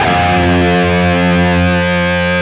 LEAD F 1 CUT.wav